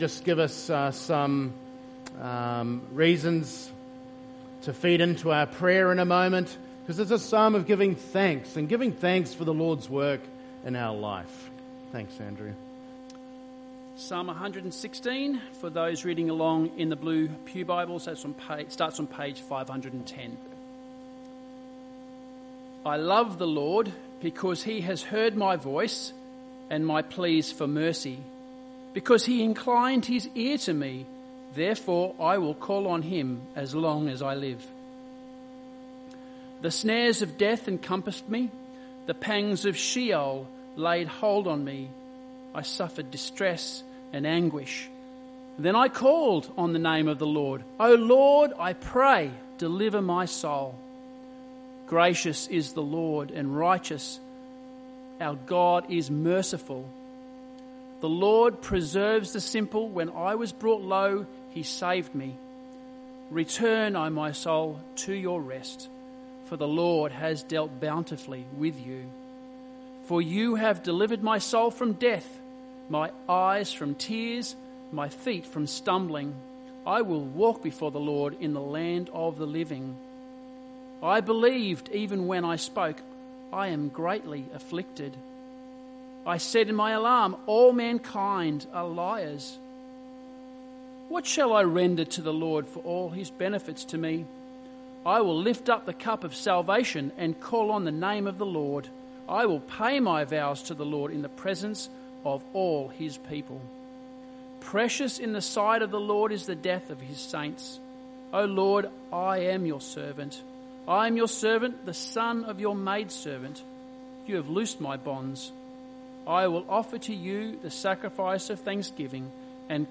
Close Log In using Email Dec 31, 2025 2Cor. 4:13-18 – Living for the Unseen Years MP3 SUBSCRIBE on iTunes(Podcast) Notes 31st December 2025 - New Year's Eve Service Readings: Psalm 116 2 Corinthians 4:13-18